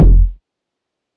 Kicks
〔ᴋɪᴄᴋ〕 SSL-F.wav